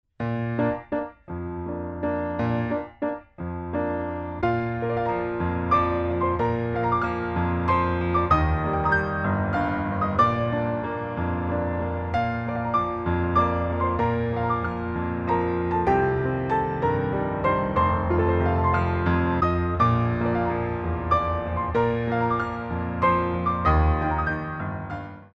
Grand Allegro
3/4 - 128 with repeat